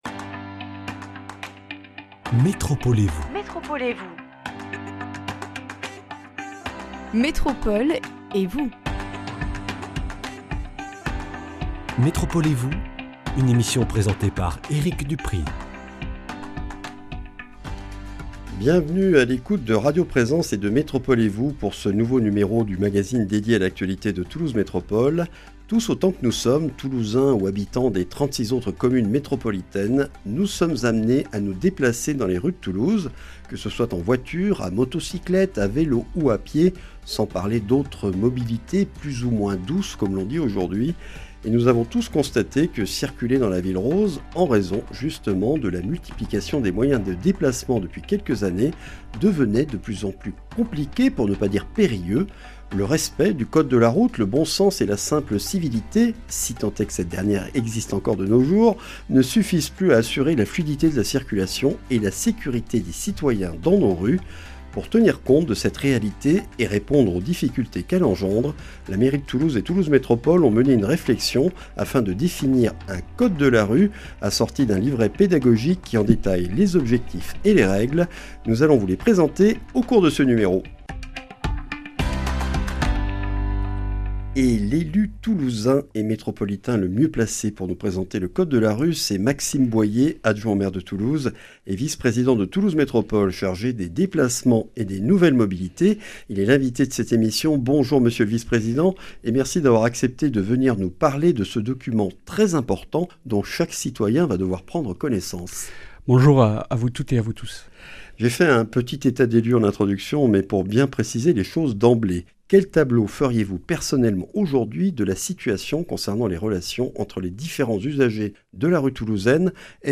Maxime Boyer, adjoint au maire de Toulouse, vice-président de Toulouse Métropole chargé des Déplacements et des Nouvelles mobilités, nous présente ses règles et objectifs destinés à tous les usagers de la rue.